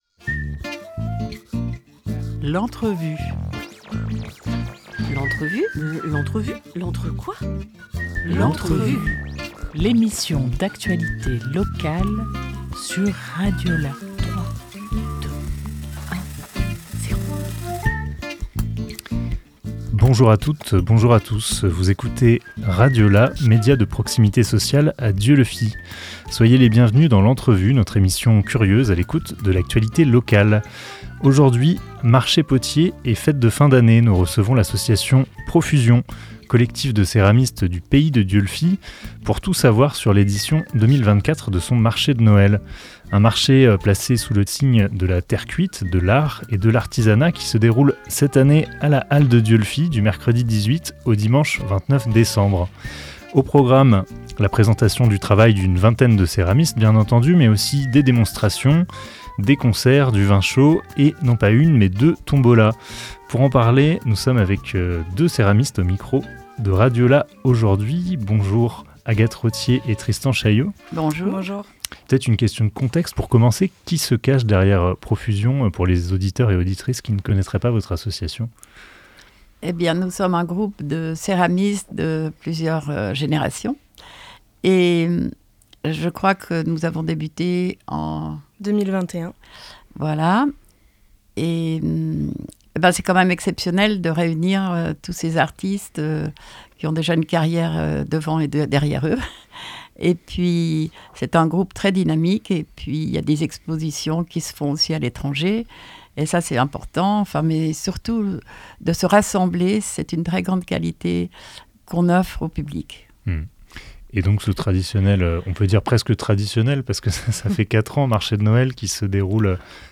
10 décembre 2024 11:32 | Interview